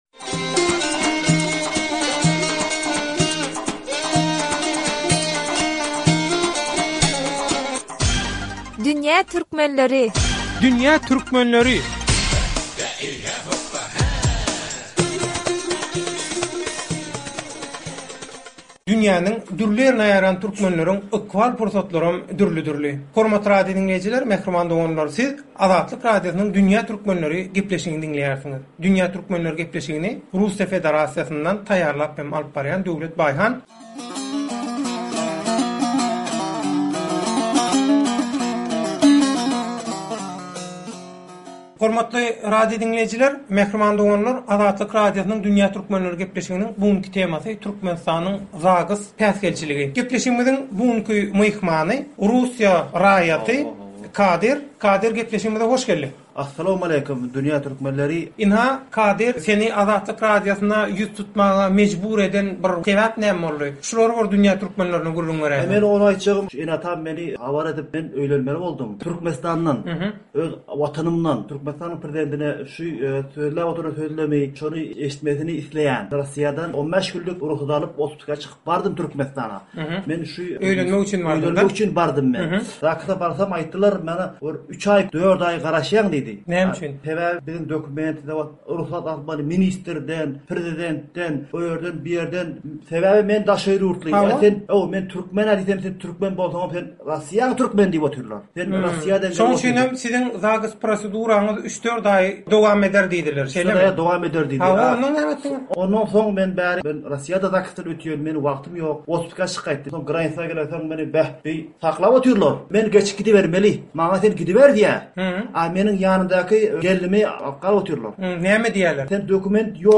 Azatlyk radiosy bilen söhbetdeşlikde